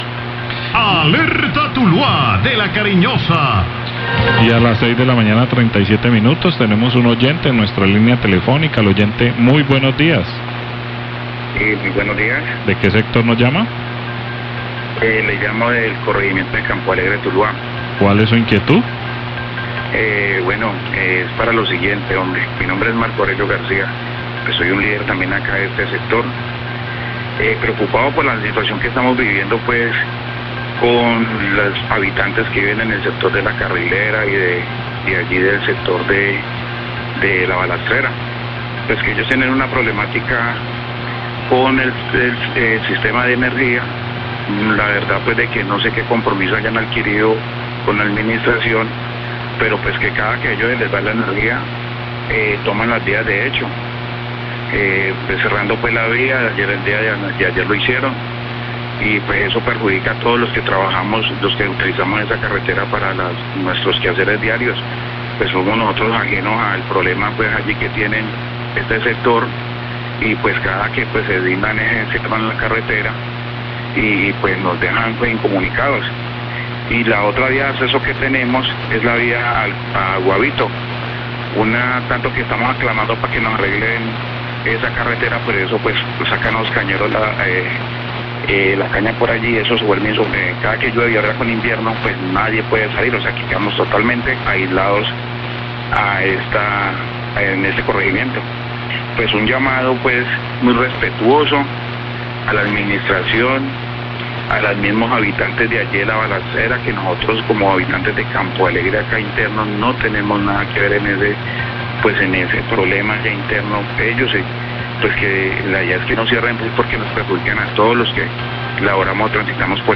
Oyente se queja por bloqueos en La Balastrera y señala que transporte de caña destruyó la otra vía de acceso, La Cariñosa, 637am
Oyente se queja por los frecuentes bloqueos en el sector de la balastrera que impiden el paso para los habitantes de Campo Alegre, esto debido a los reclamos por su derecho al servicio de energía.